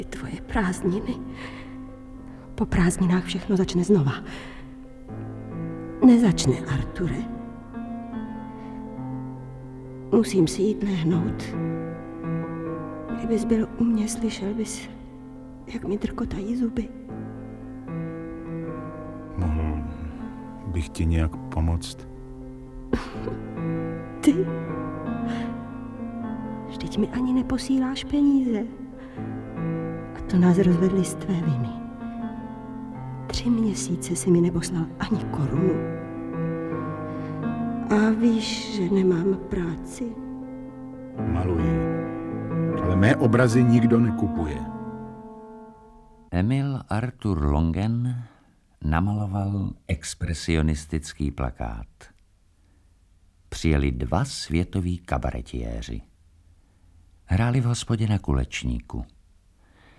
Audiobook
Read: Petr Čepek